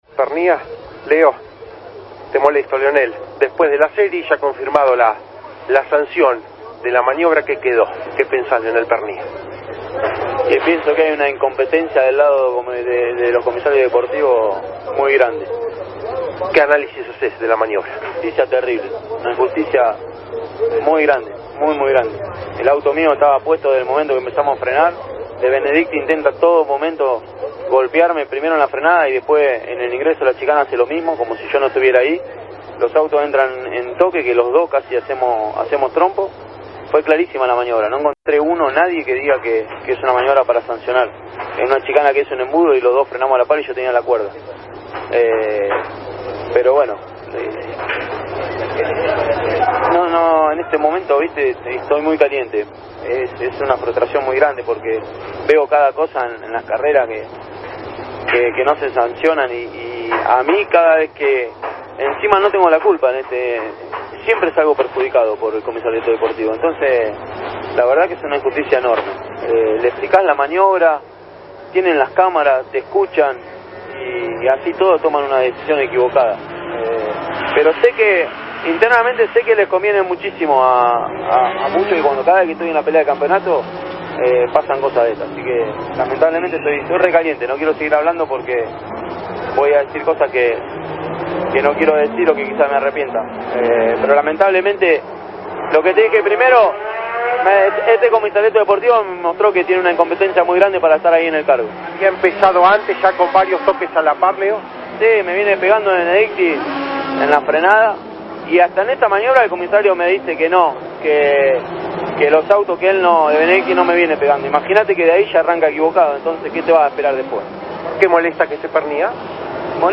Habló de incompetencias, calentura y frustración. El descontento era tal que intentó medir palabras 'para no arrepentirse' luego.
«Internamente sé que les conviene a muchos, porque cada vez que estoy en la pelea del campeonato pasan cosas de estas» – Leonel Pernía en Campeones Radio